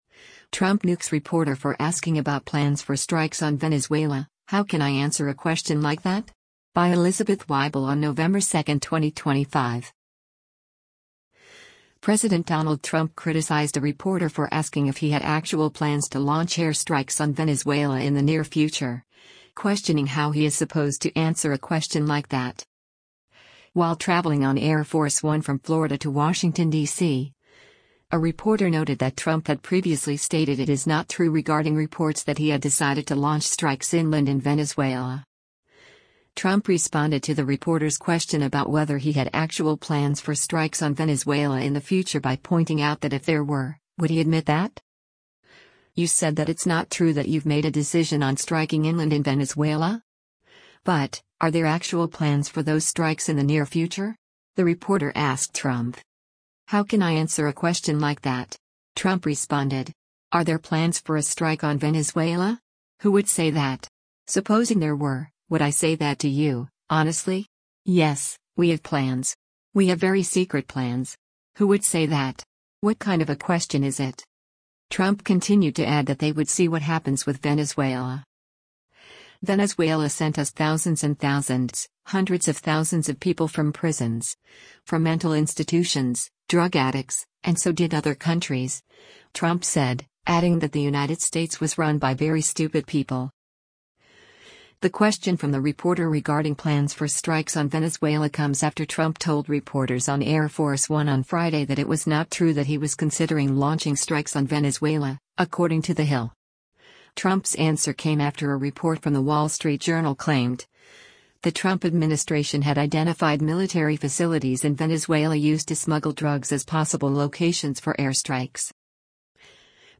US President Donald Trump speaks to members of the press aboard Air Force One as he heads